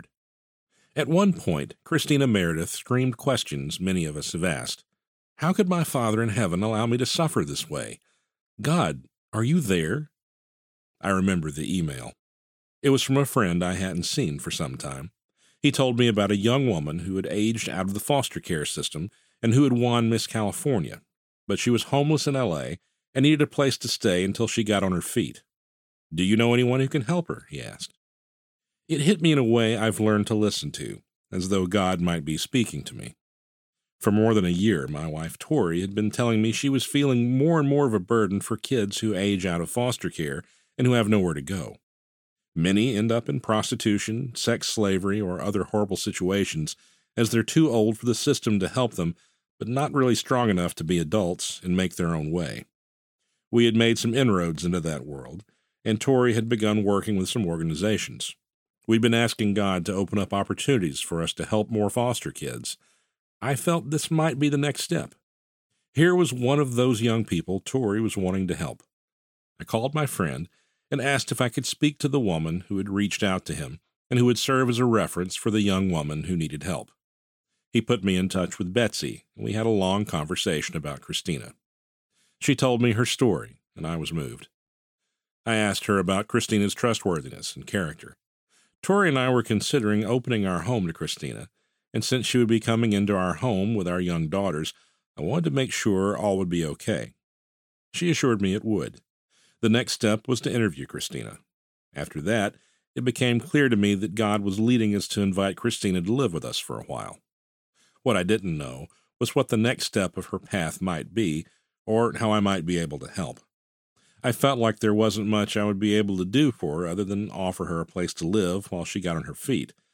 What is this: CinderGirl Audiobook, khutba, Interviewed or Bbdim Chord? CinderGirl Audiobook